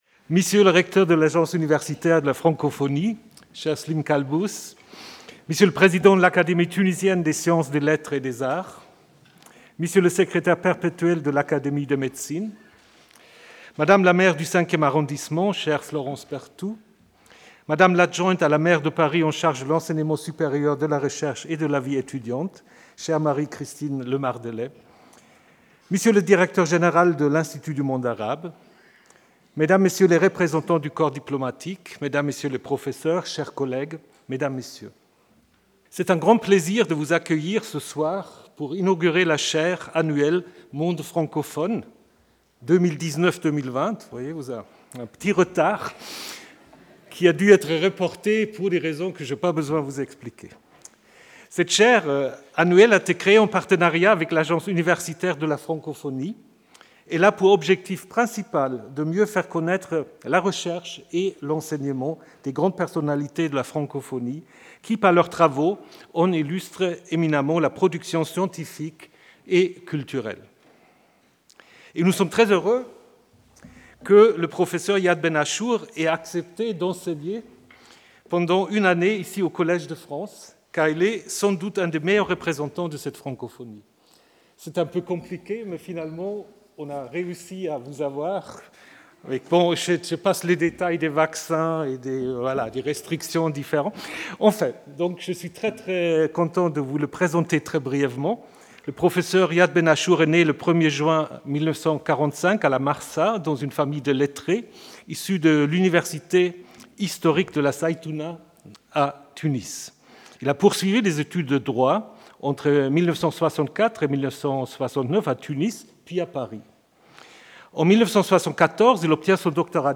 La leçon inaugurale s’achèvera par une réflexion générale sur les révolutions dans le monde arabe actuel.